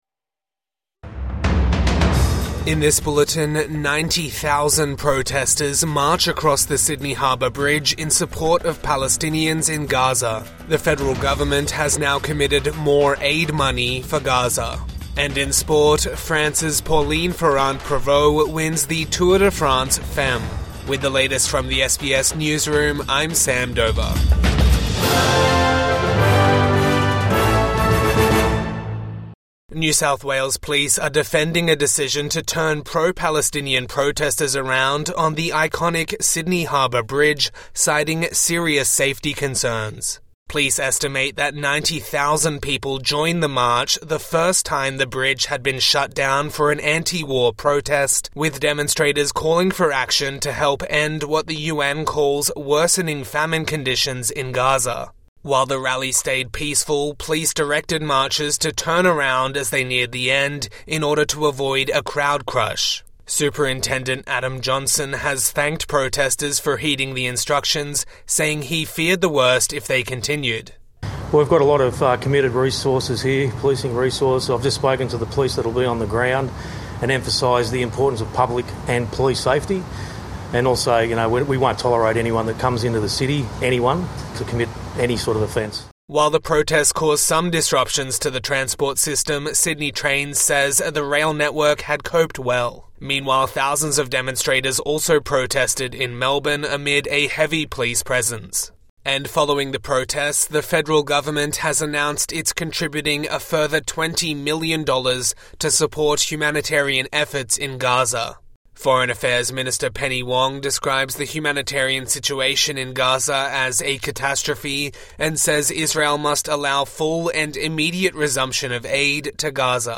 Police defend decision to turn Sydney Harbour Bridge for Gaza | Morning News Bulletin 4 August 2025